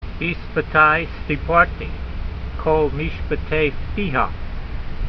Sound (Psalm 119:13) Transliteration: bi sfa t ai see par tee , kol meeshpet ay - fee ha ( seen—dot on top left , not sheen) Vocabulary Guide: With my lips I have declared all the mandate s of your mouth Translation: With my lips I have declared all the mandates of your mouth.
v13_voice.mp3